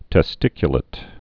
(tĕ-stĭkyə-lĭt)